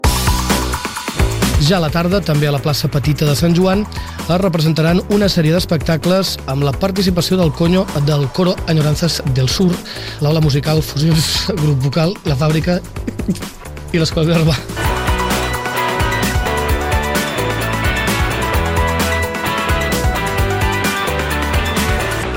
Espai emès amb motiu del Dia mundial de la ràdio 2022. Una errada en directe d'un presentador de Ràdio Palamós.
Entreteniment